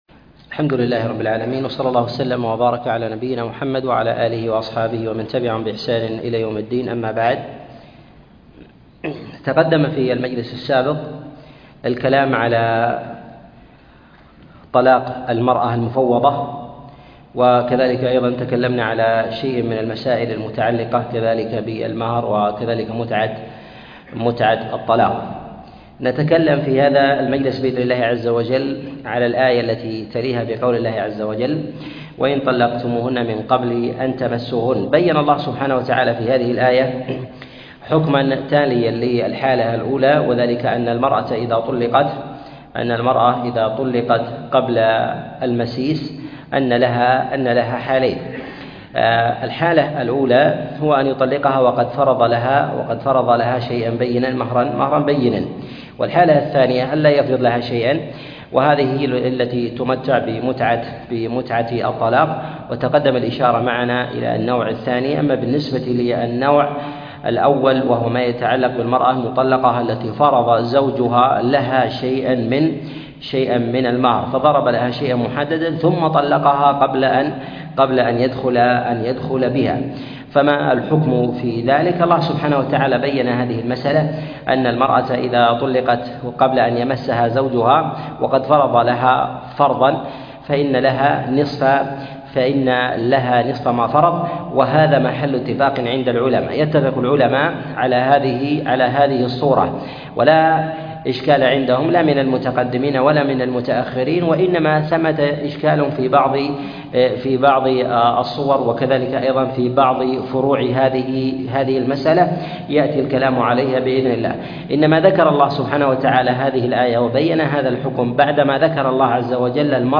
تفسير سورة البقرة 38 - تفسير آيات الأحكام - الدرس الثامن والثلاثون - الشيخ عبد العزيز بن مرزوق الطريفي